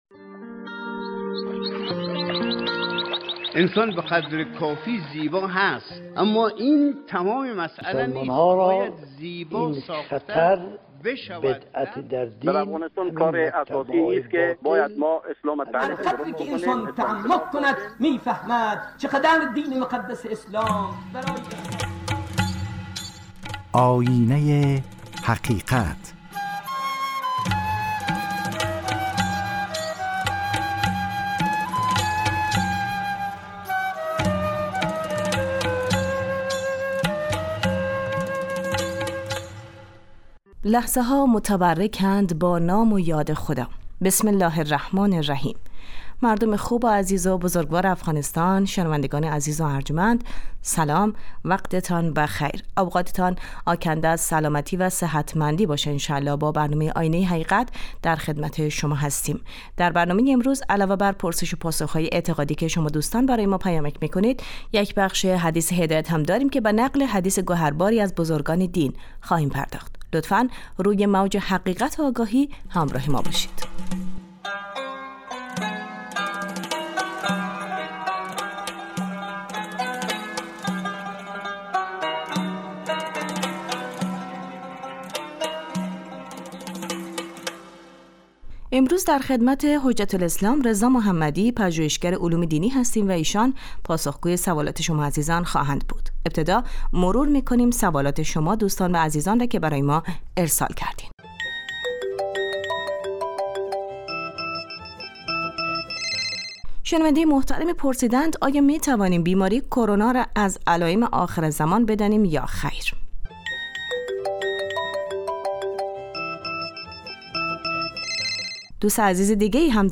شرح حدیثی نیز با صدای رهبر معظم انقلاب حضرت آیت الله خامنه ای زینت بخش برنامه خواهد بود .